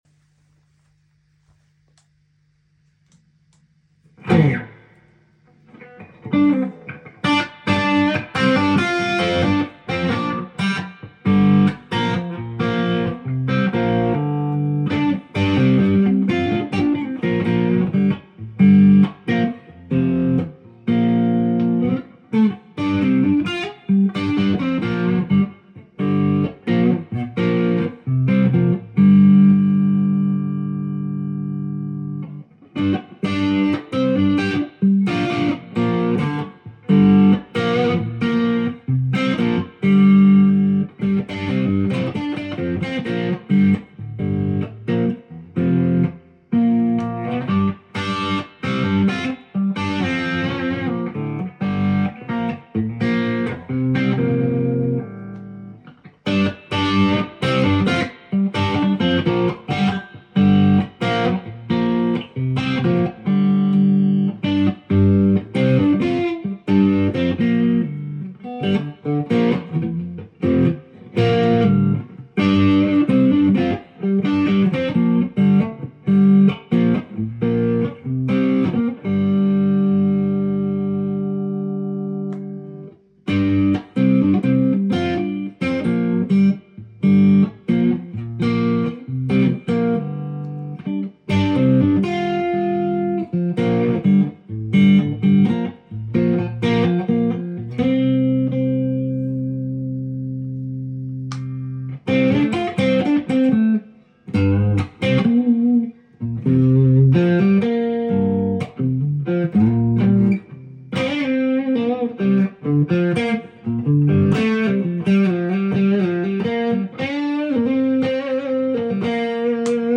Les Paul is now MORE PAUL after modifications and refret. sound sample